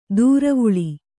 ♪ dūravuḷi